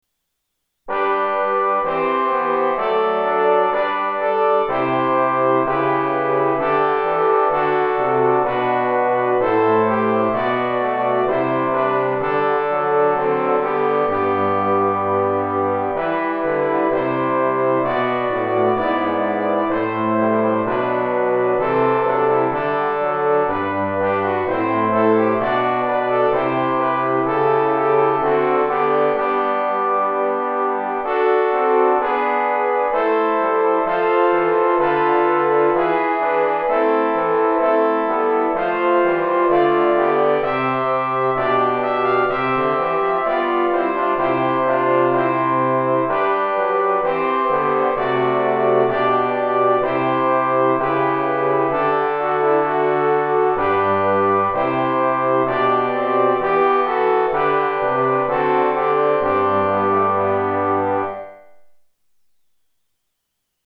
Sample Sound for Practice 練習用参考音源：MIDI⇒MP3　Version A.17
1　 Trombone